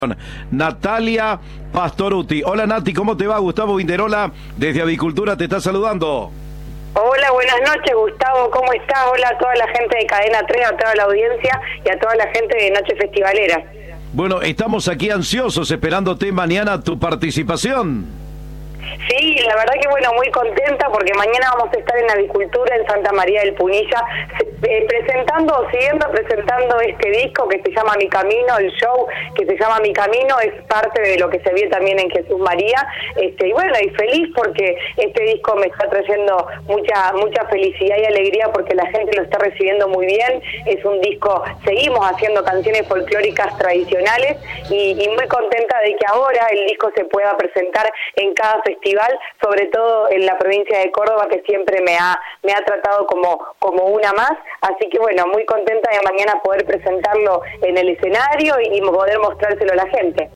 La cantante estará este sábado en el Festival de Santa María de Punilla.  En diálogo con Cadena 3 contó sus expectativas por presentar su nuevo trabajo.